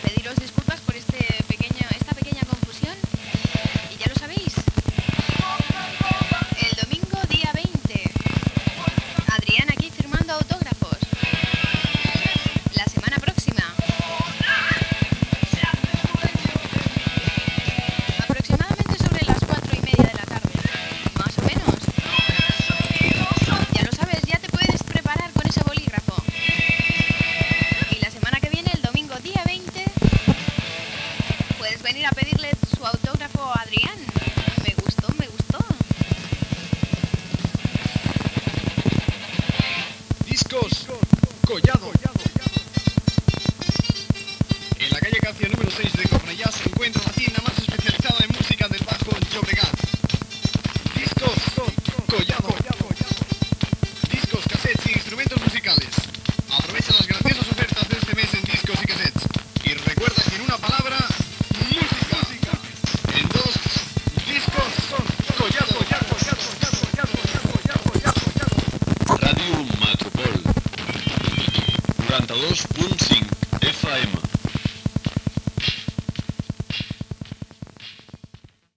Comercial
Propera signatura de discos, publicitat i indicatiu.
FM